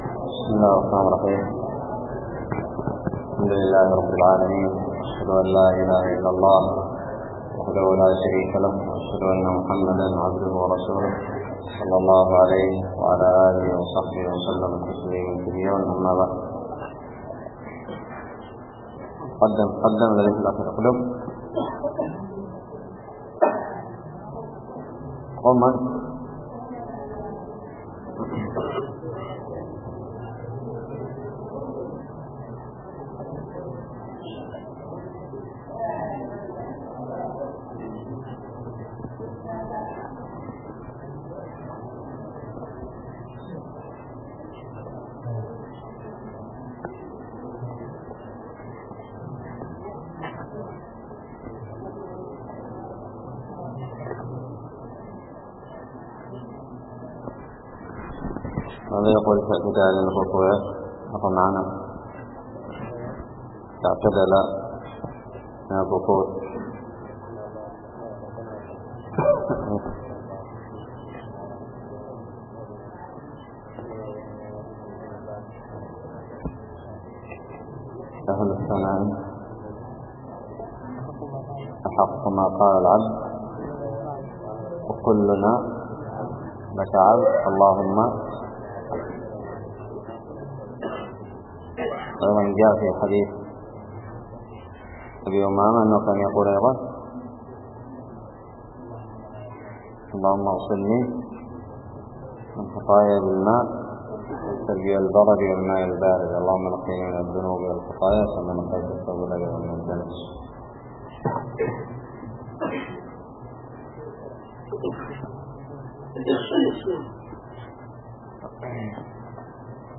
الدرس السادس والسبعون من كتاب الصلاة من الدراري
ألقيت بدار الحديث السلفية للعلوم الشرعية بالضالع